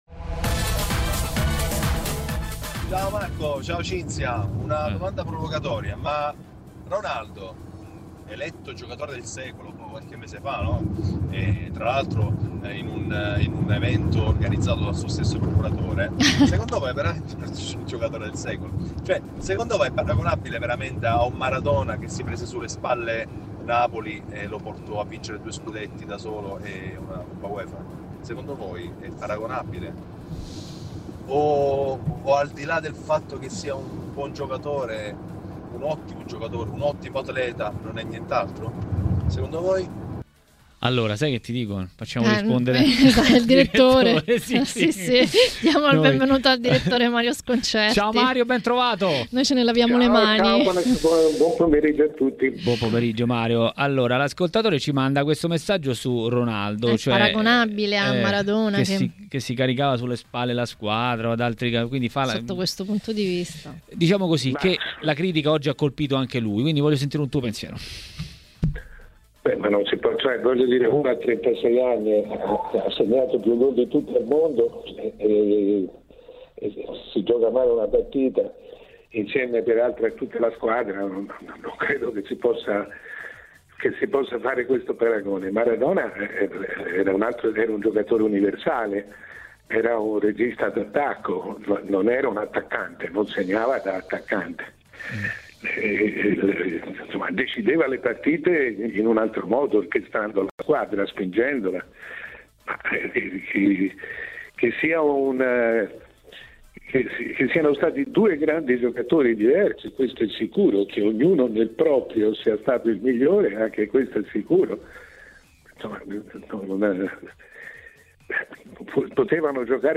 Il direttore Mario Sconcerti a Maracanà, nel pomeriggio di TMW Radio, ha commentato le ultime vicende in casa Juventus: